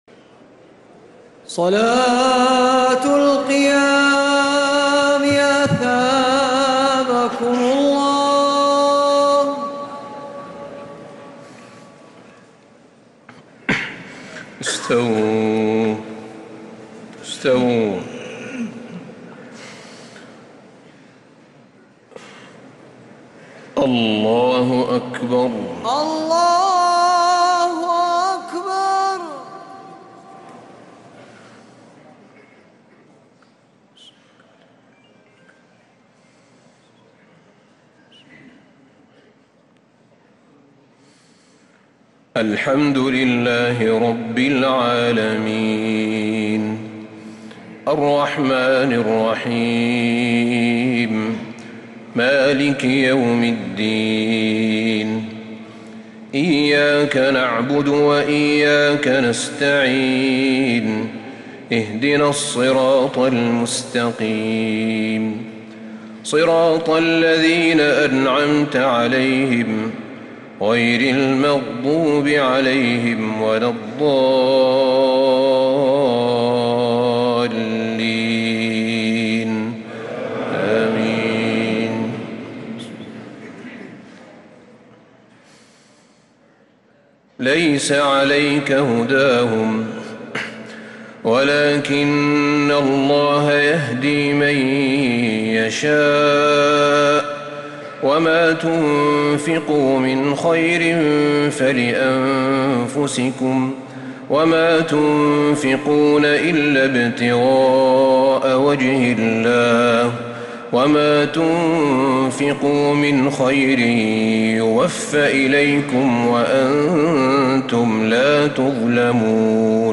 تلاوات التراويح والتهجد كاملة للشيخ أحمد بن طالب بن حميد لعام 1446هـ > تراويح الحرم النبوي عام 1446 🕌 > التراويح - تلاوات الحرمين